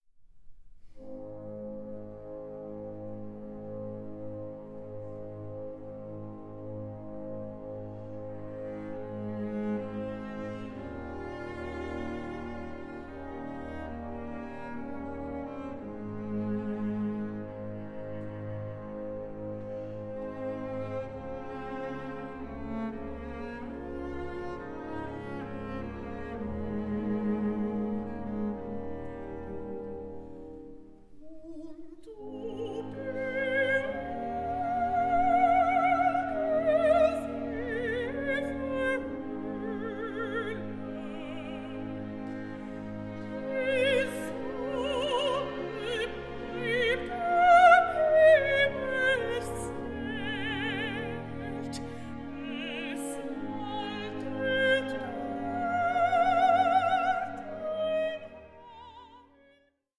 THE GERMAN ROMANTIC OPERA PAR EXCELLENCE